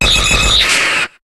Cri de Dodrio dans Pokémon HOME.